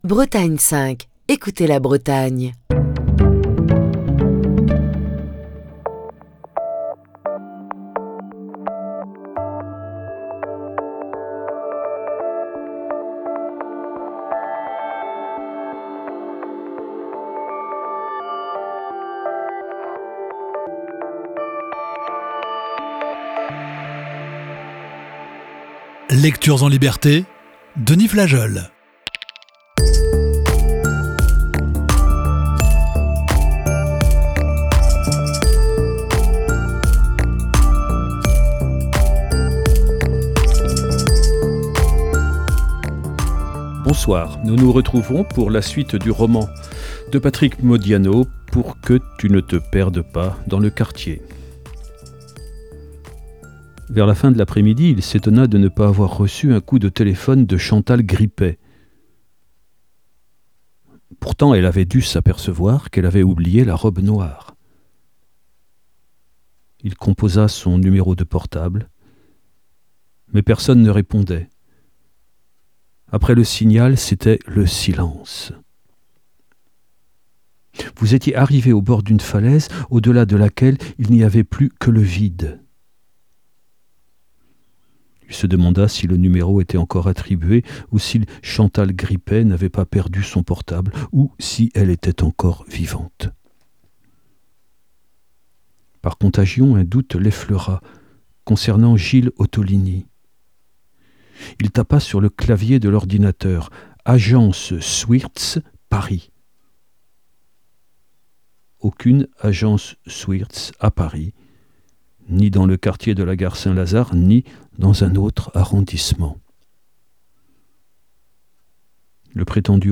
la lecture de la cinquième partie de ce récit